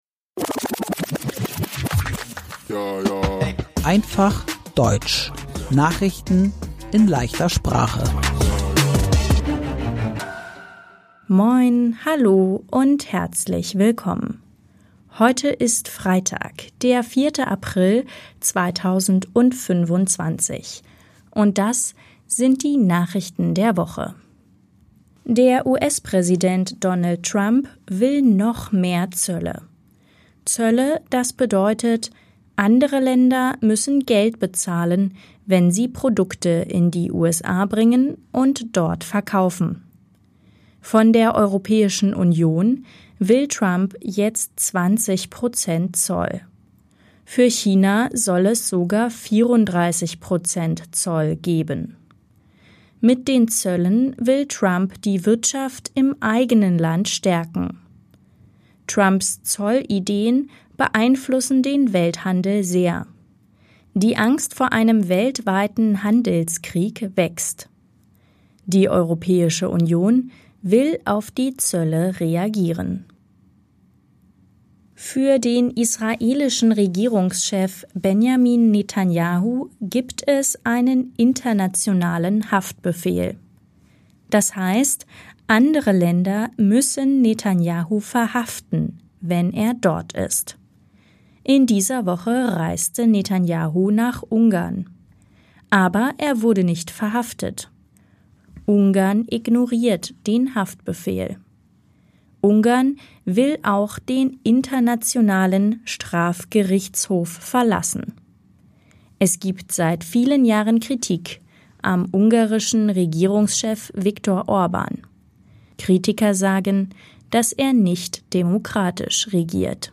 Am Sonntag war Europa-Wahl – Einfach Deutsch: Nachrichten in leichter Sprache – Podcast